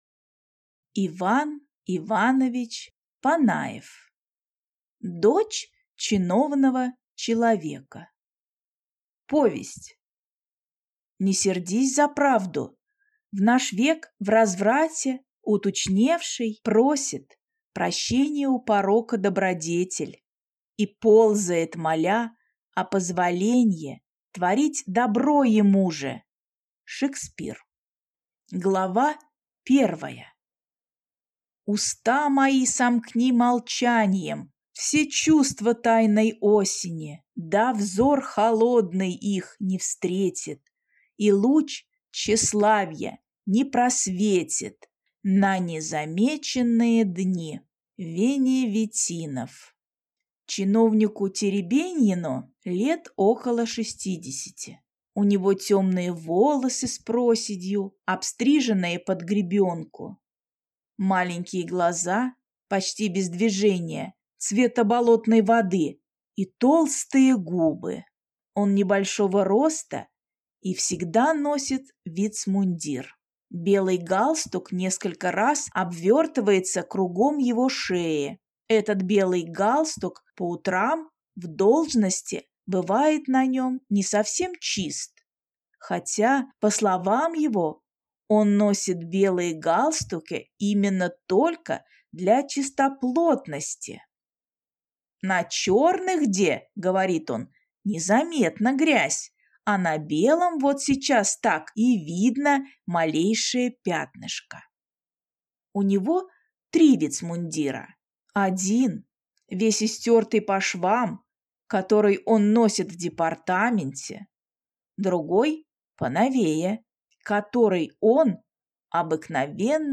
Аудиокнига Дочь чиновного человека | Библиотека аудиокниг